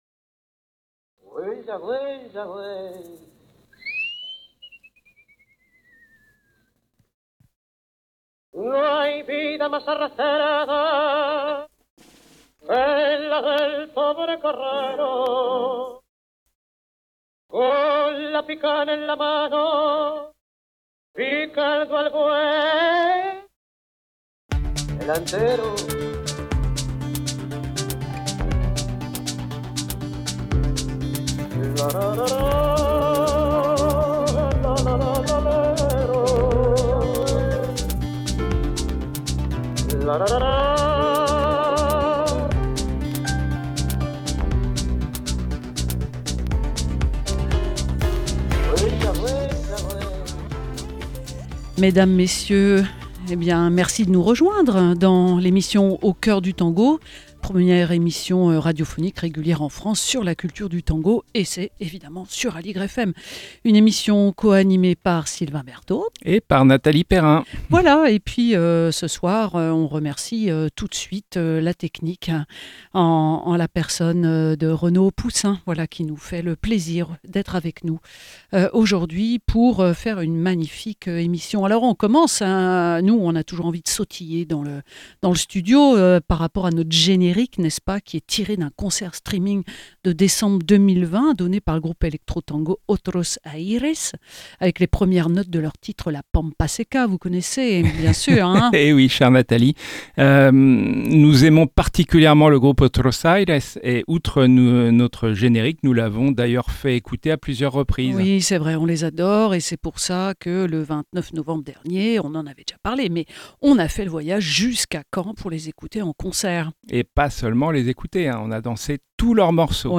Nous aborderons les différentes facettes de son travail, avec des illustrations musicales de ses interprétations et compositions, dont un live inédit enregistré dans notre studio.